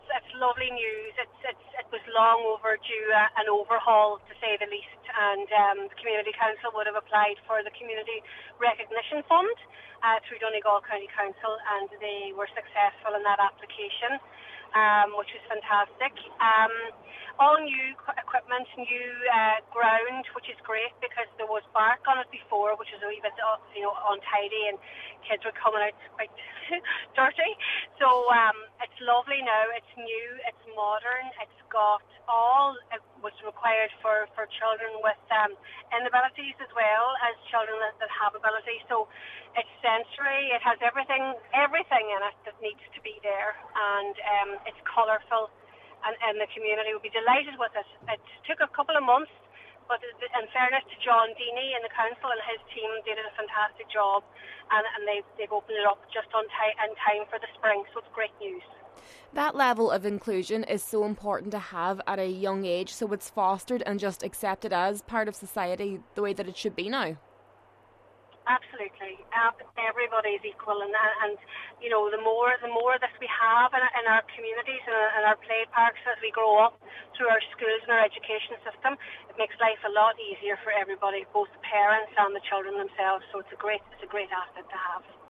Cathaoirleach of Donegal County Council, Cllr Niamh Kennedy says inclusion on a community level is imperative: